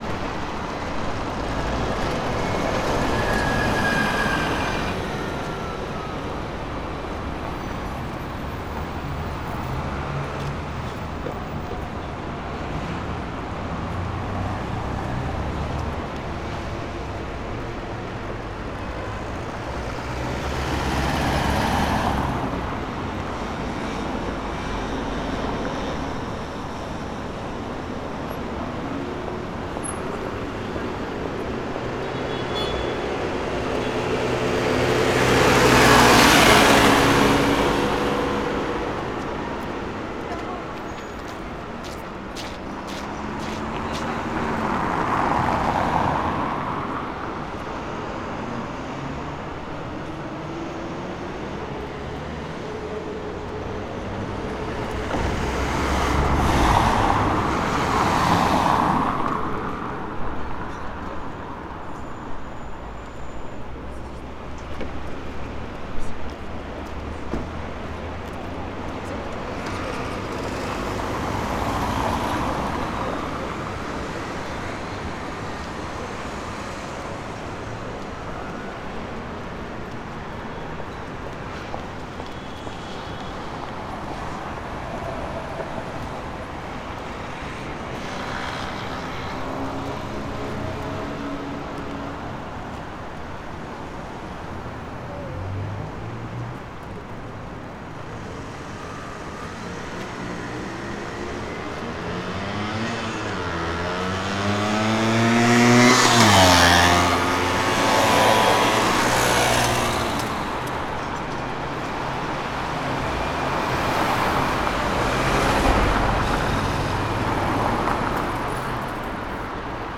Paris_stret_large2.L.wav